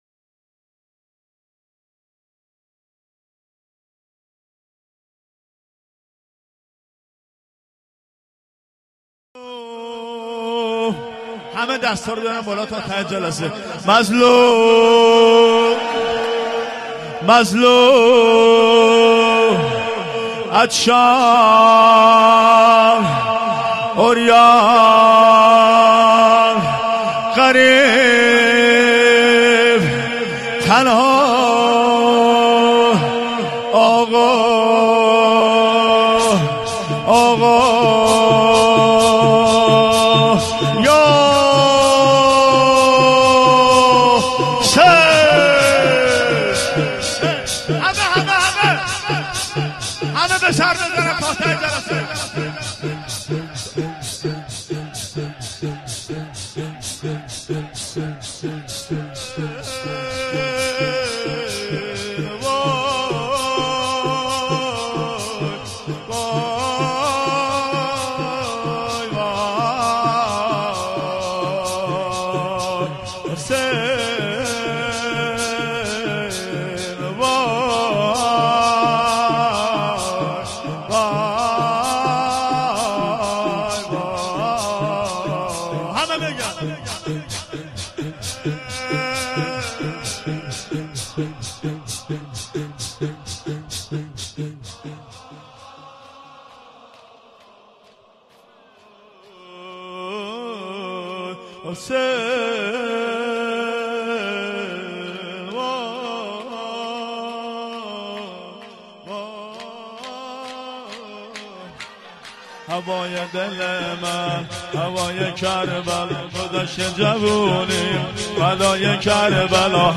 مداحی شور
محرم 1394 | شب سوم | هیأت حسین جان (ع) قزوین